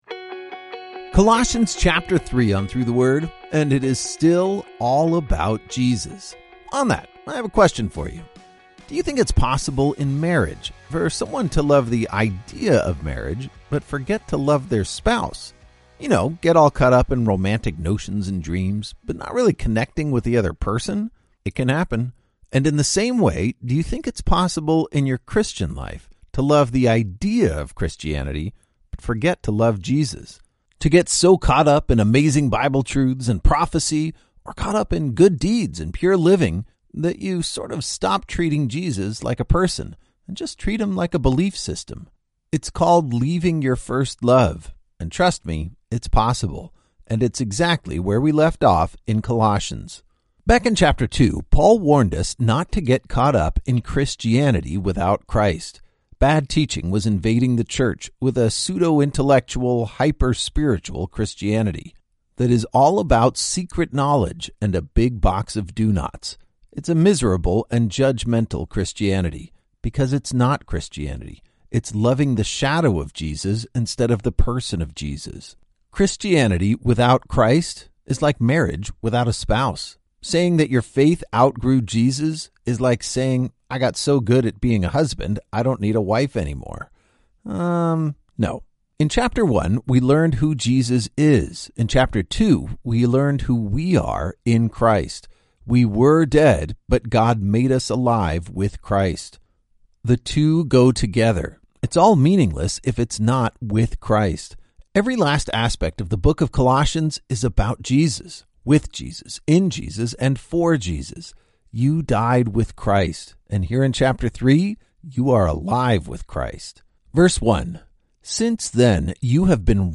When the Bible is confusing, Through the Word explains it with clear, concise audio guides for every chapter.